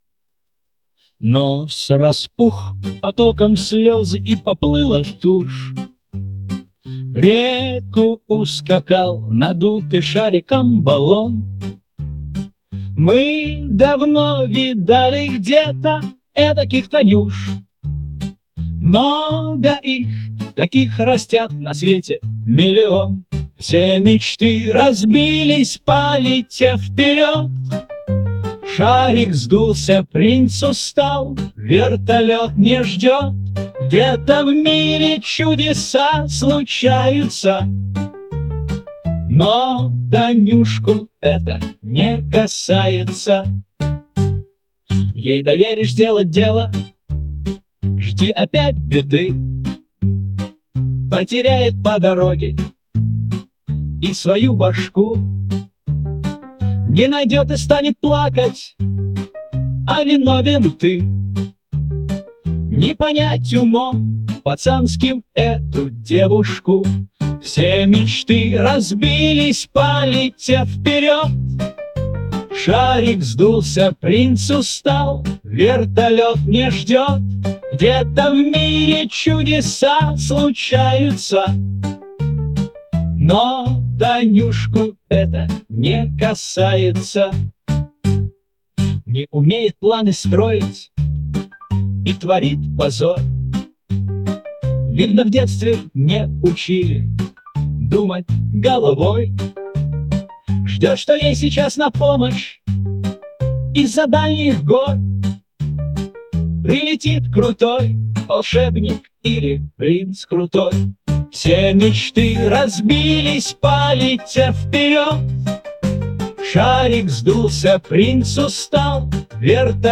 !!!Ремикс на старый стишок.